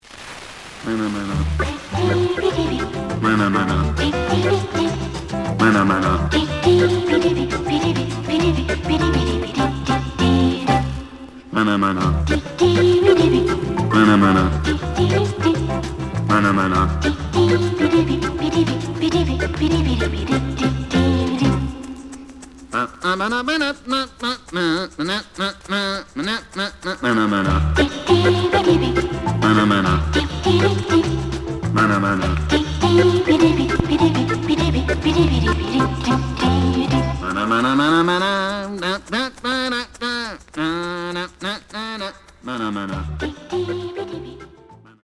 The audio sample is recorded from the actual item.
●Genre: Jazz Other